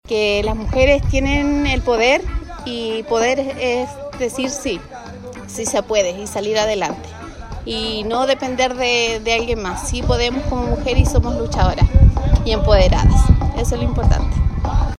Hasta Pichasca, en la comuna de Río Hurtado, llegaron mujeres de Punitaqui, Combarbalá, Monte Patria y Ovalle, quienes formaron parte de un valioso encuentro provincial, impulsado desde el Servicio Nacional de la Mujer y la Equidad de Género (SernamEG) junto a los municipios ejecutores de la provincia.